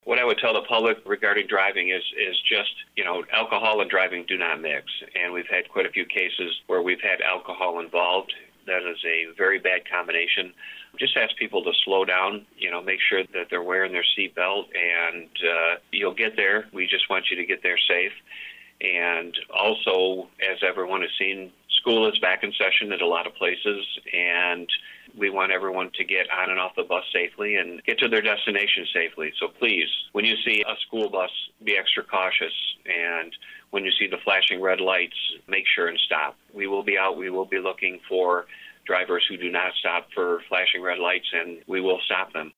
Lenawee County Sheriff Troy Bevier talked about drinking and driving, being aware of school children and buses, and wearing your seat belt…
Troy-Bevier-Driving-Safety-1-9-1-21.mp3